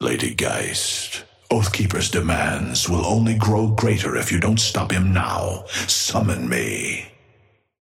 Amber Hand voice line - Lady Geist, Oathkeeper's demands will only grow greater if you don't stop him now.
Patron_male_ally_ghost_start_01.mp3